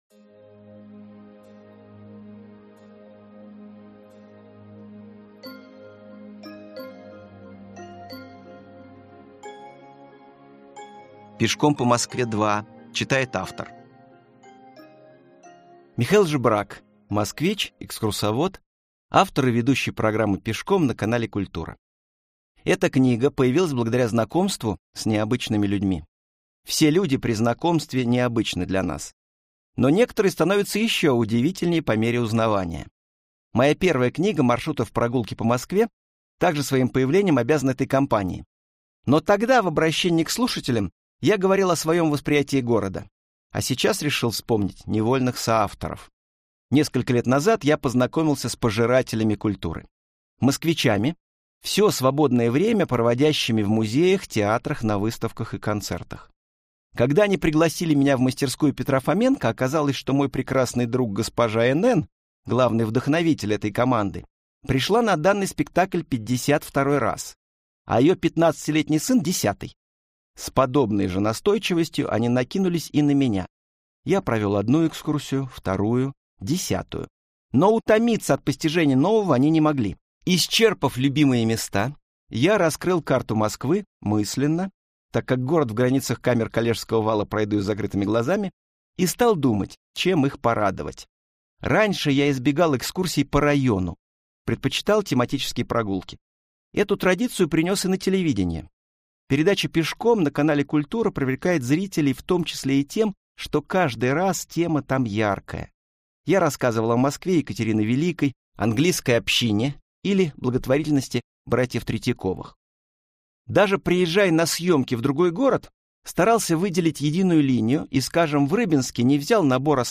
Аудиокнига Пешком по Москве – 2 | Библиотека аудиокниг